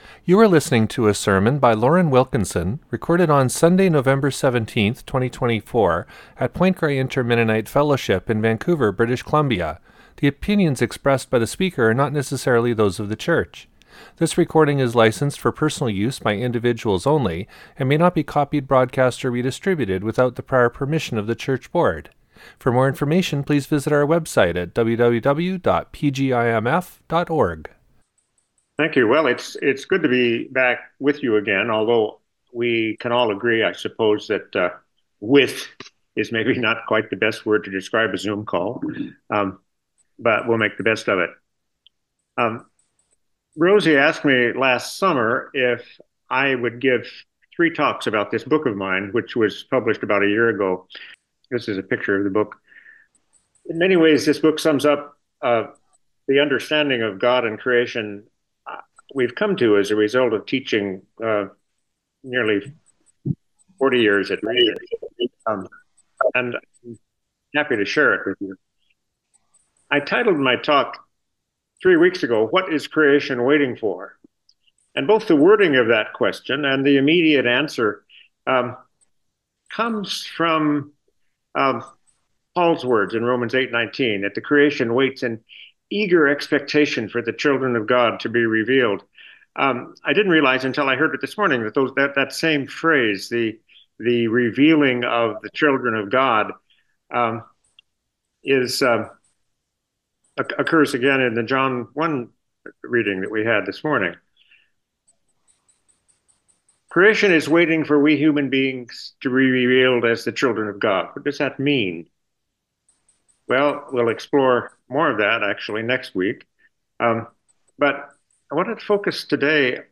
Sermon Recording: Download